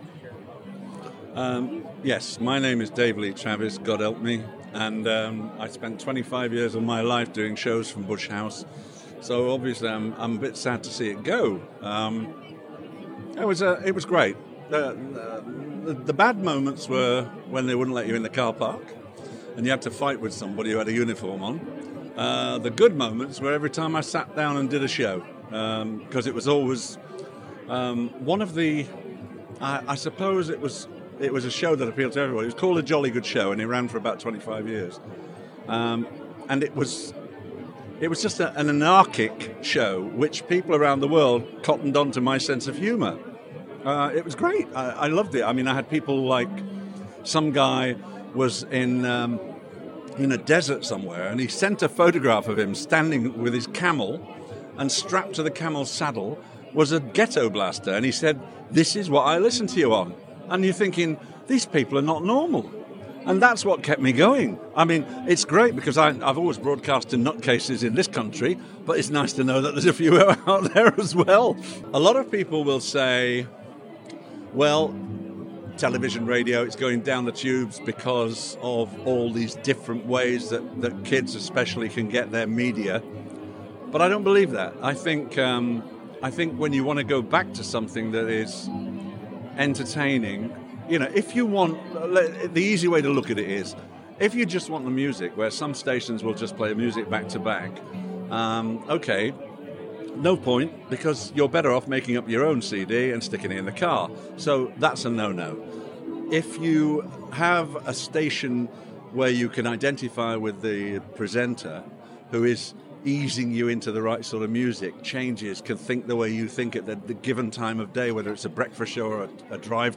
I manage to grab a few moments with Dave Lee Travis at the Farewell to Bush House party.